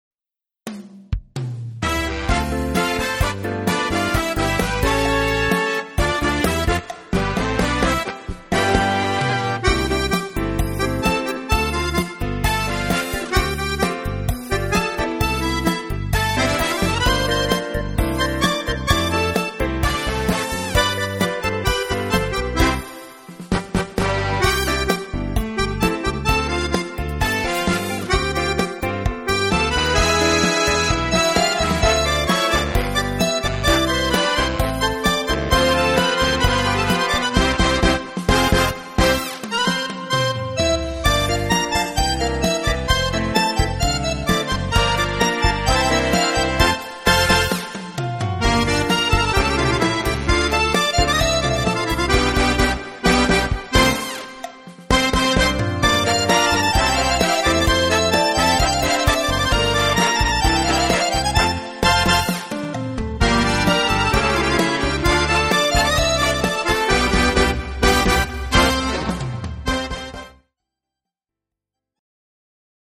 CHACHA